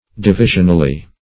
\Di*vi"sion*al*ly\
divisionally.mp3